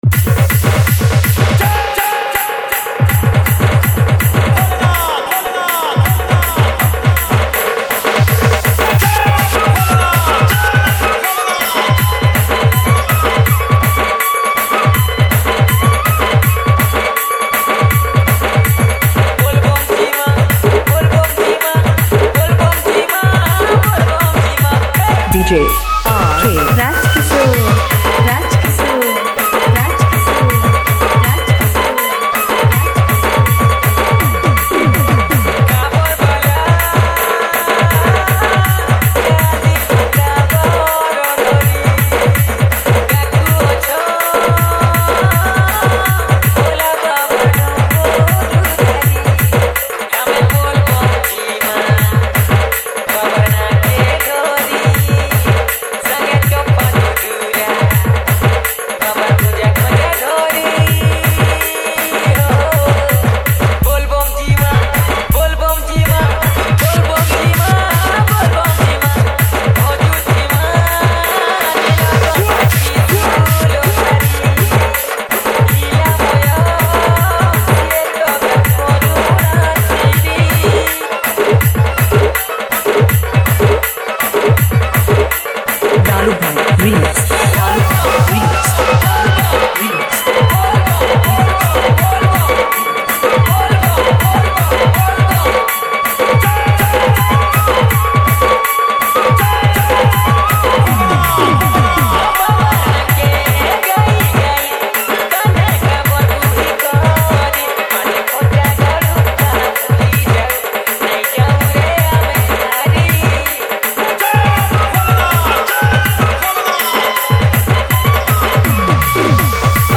Category:  Odia Bhajan Dj 2019